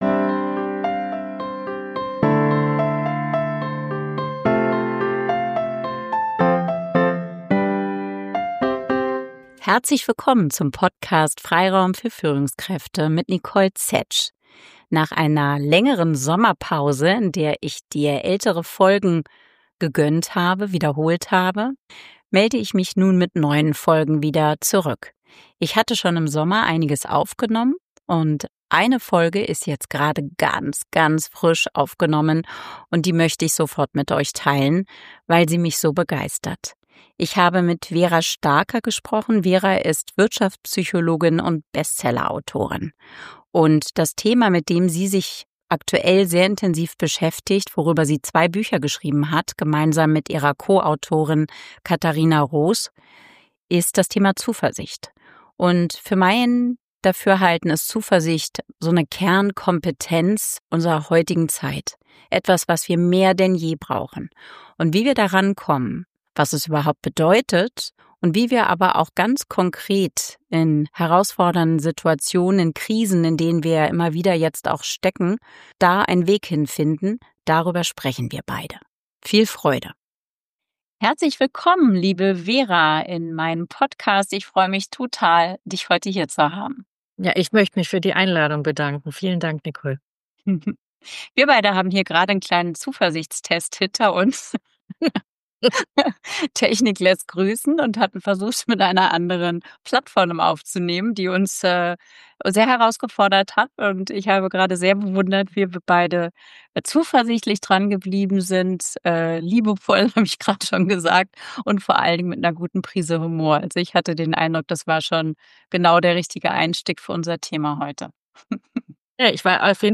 Ein hoch spannendes Gespräch, das Mut und Zuversicht für herausfordernde Zeiten gibt.